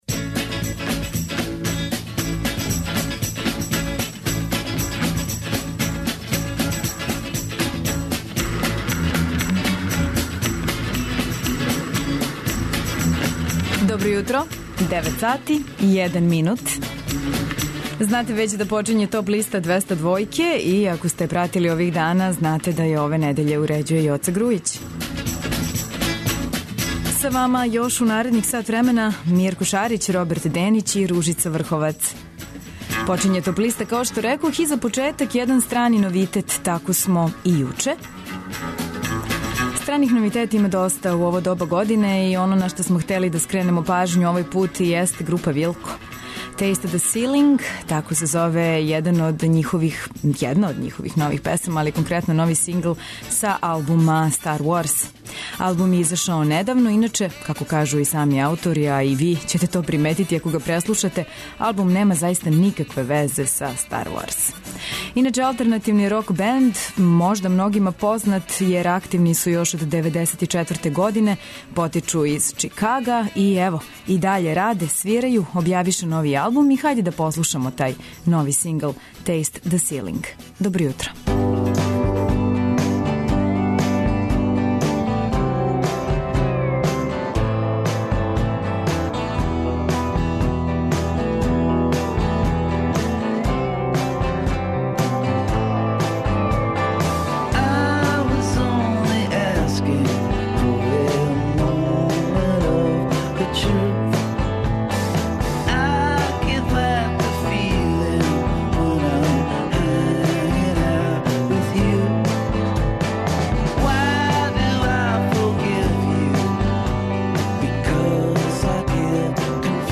Најавићемо актуелне концерте у овом месецу, подсетићемо се шта се битно десило у историји рок музике у периоду од 07. до 11. септембра. Ту су и неизбежне подлисте лектире, обрада, домаћег и страног рока, филмске и инструменталне музике, попа, етно музике, блуза и џеза, као и класичне музике.